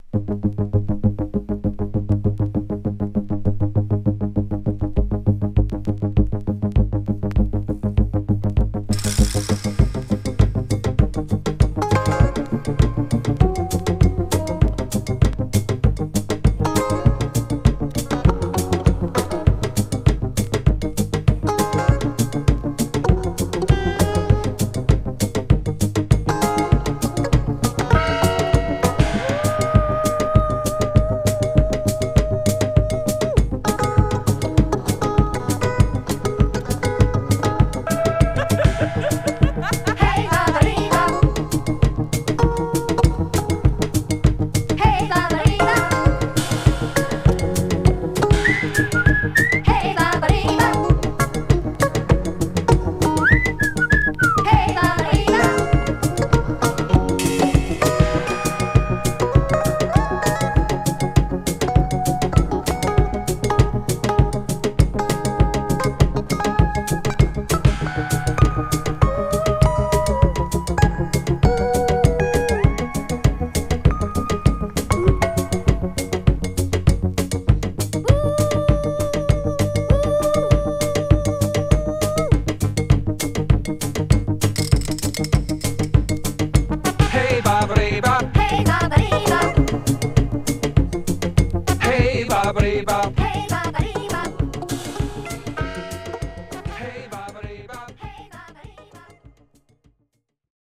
> CROSSOVER/ACID JAZZ/CLUB JAZZ